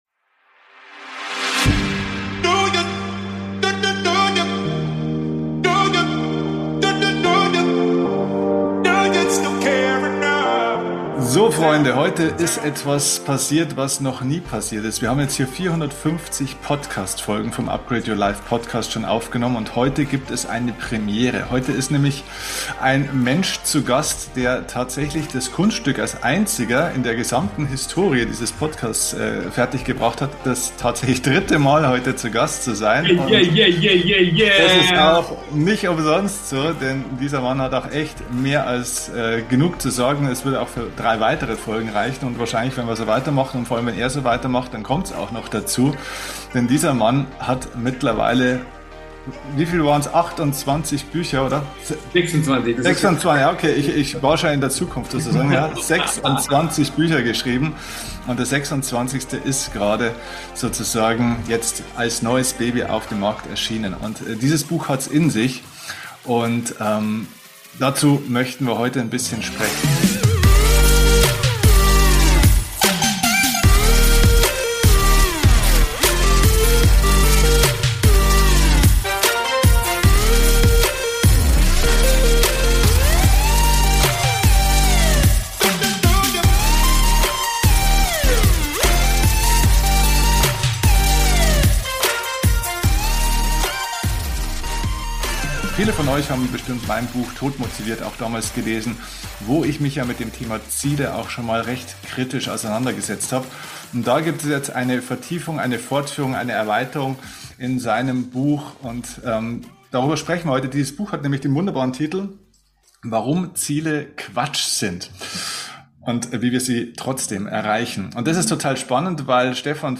#452 Warum Ziele Quatsch sind – Interview